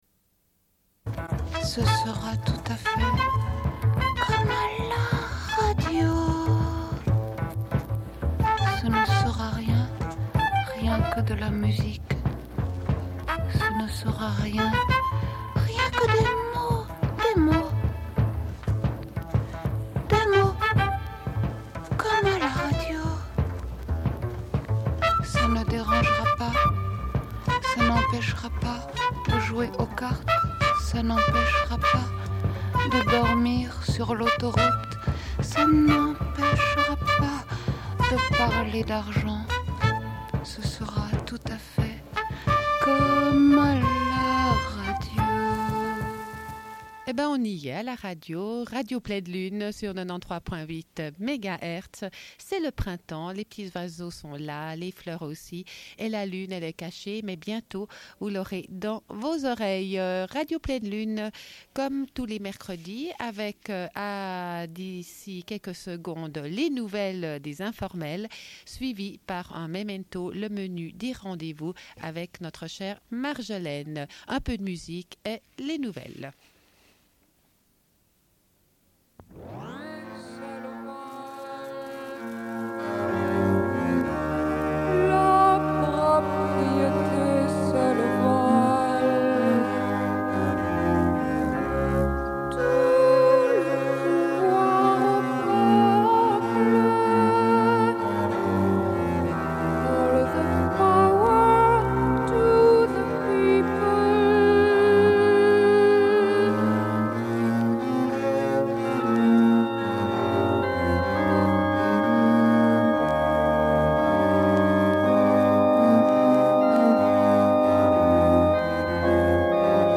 Bulletin d'information de Radio Pleine Lune du 23.03.1994 - Archives contestataires
Une cassette audio, face B31:20